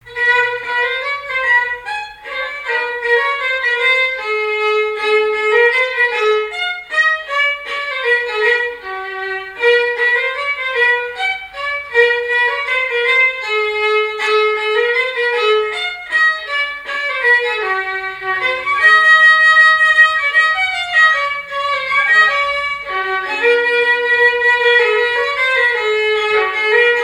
danse : java
Genre strophique
répertoire musical au violon